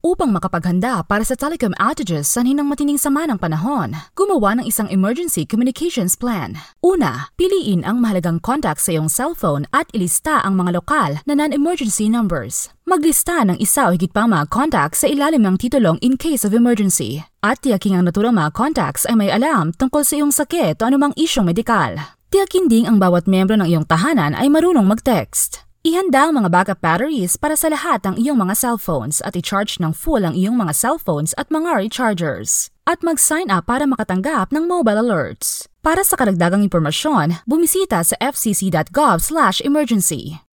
Nagpaplano nang maaga PSA, 40 segundo, audio